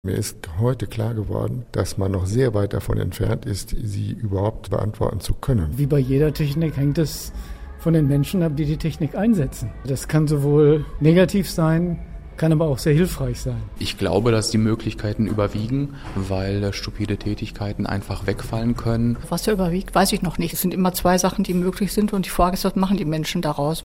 Im vollbesetzten Theatercafe diskutierte das Publikum mit dem Podium.